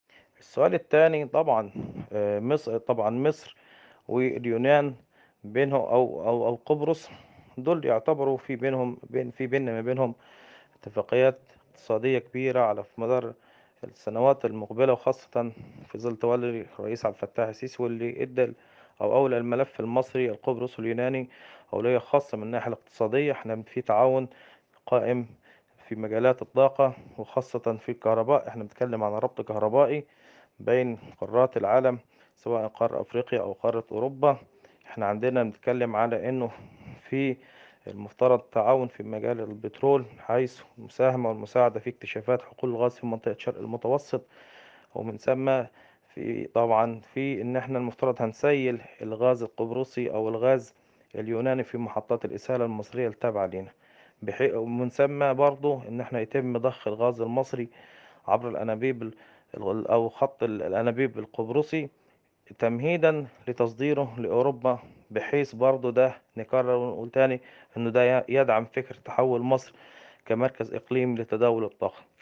حوار
محلل اقتصادي ومتخصص في قطاع الكهرباء والطاقة